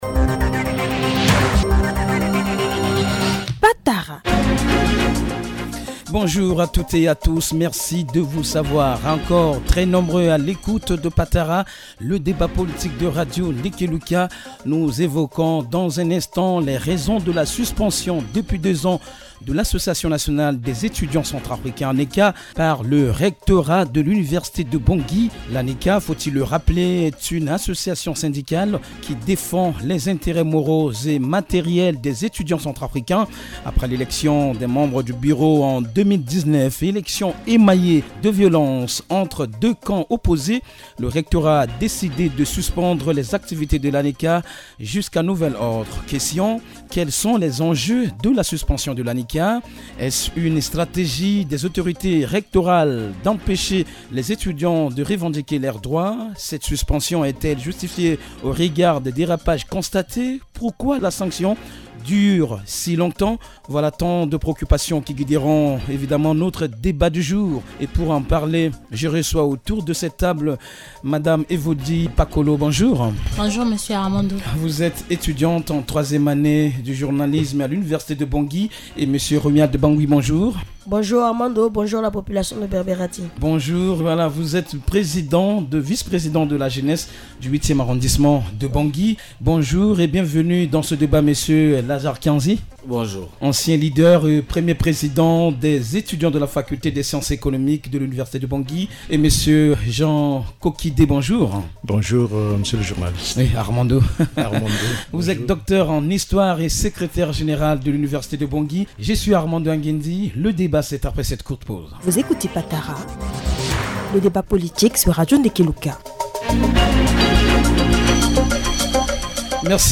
Patara, le débat politique de Radio Ndeke-Luka, évoque dans ce nouvel épisode les raisons de la suspension depuis deux ans de l’Association nationale des étudiants centrafricains (ANECA) par le rectorat de l’université de Bangui.